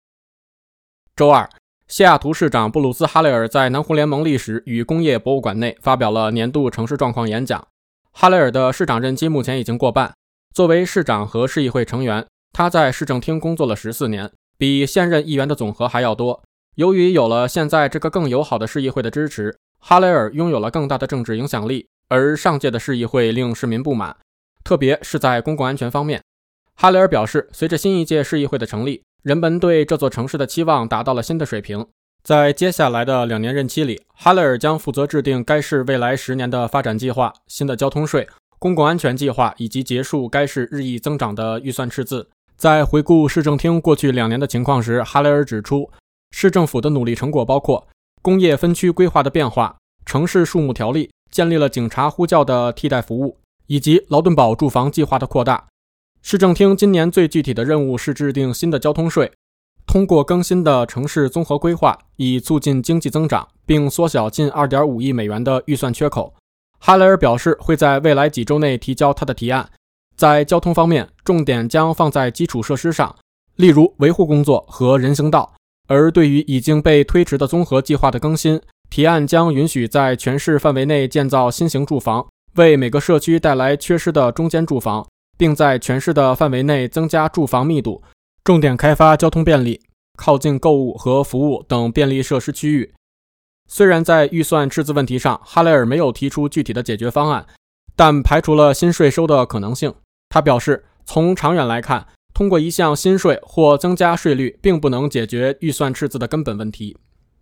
西雅圖市長哈雷爾發表年度演講（02/21/24）